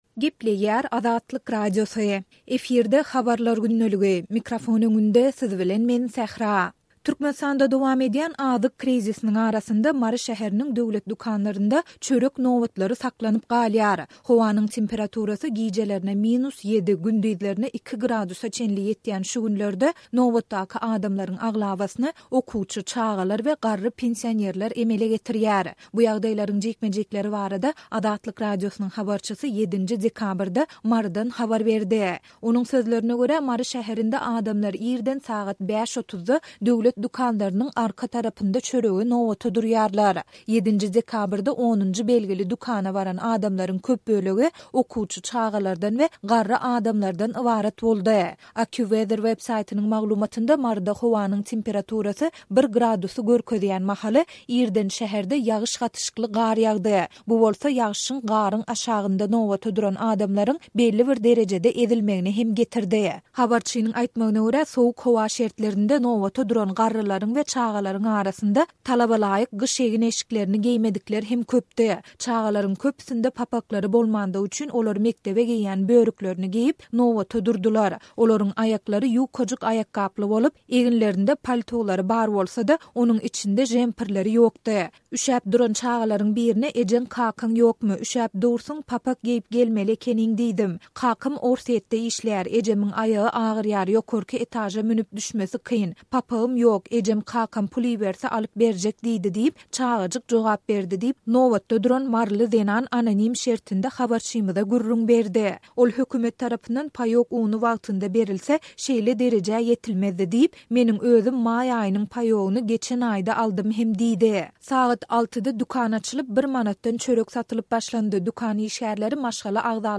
Bu ýagdaýlaryň jikme-jikleri barada Azatlyk Radiosynyň habarçysy 7-nji dekabrda Marydan habar berdi.